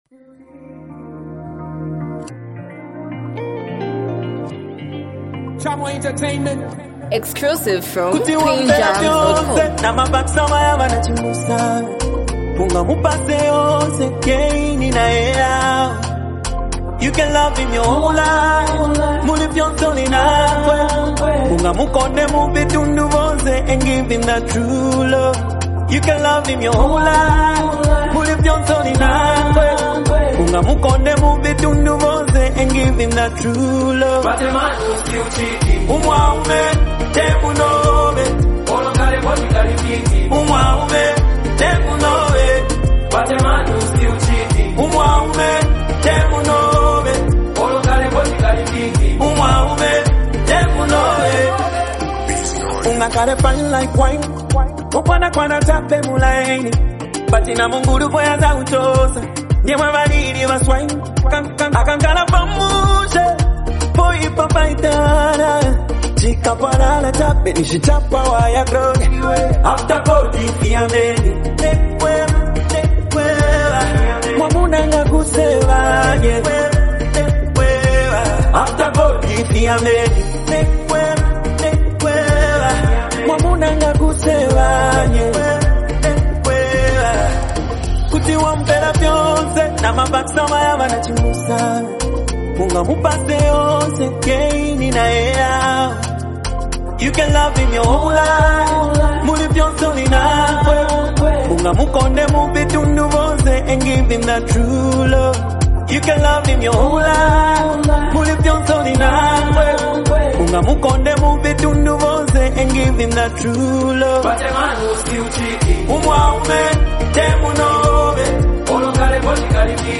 hype, street vibes, and a powerful hook
unique singing flow